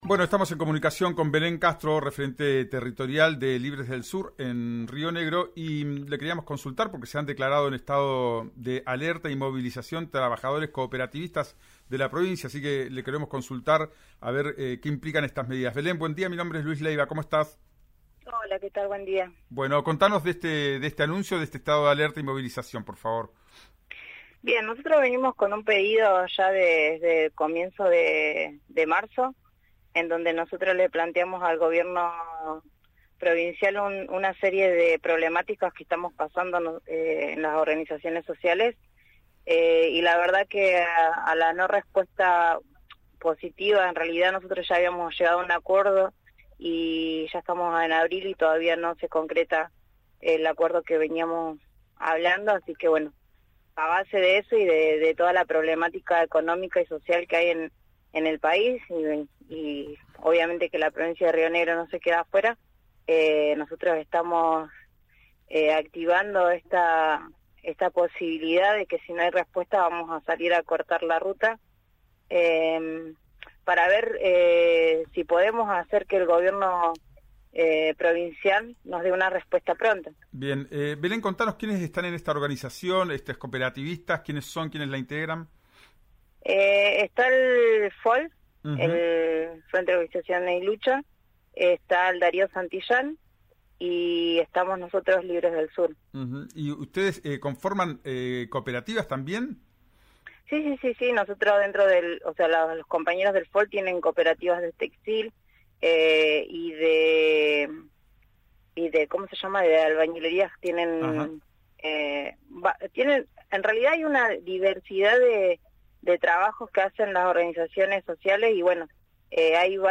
se comunicó con RÍO NEGRO en el programa «Ya es tiempo» y explicó los motivos por los cuales se declararon en estado de alerta y por qué llevaran adelante la medida.